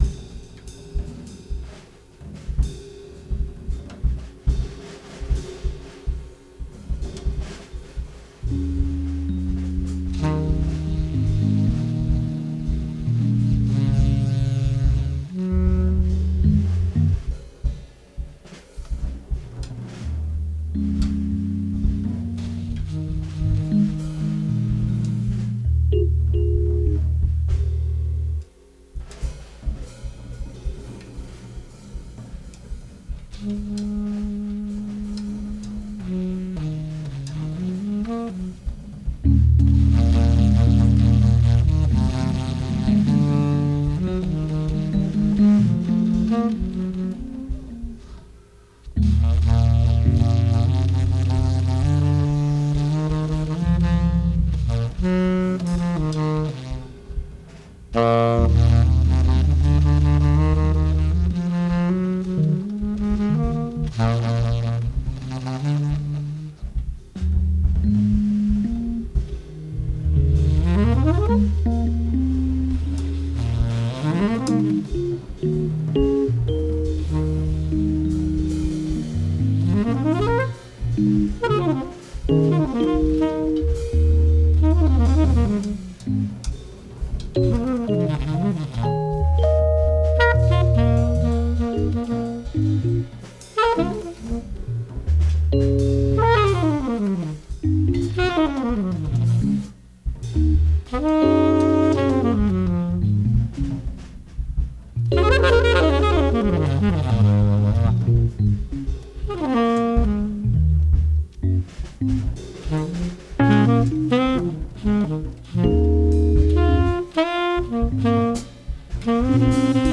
tenor saxophone, bass clarinet
keyboards, piano
drums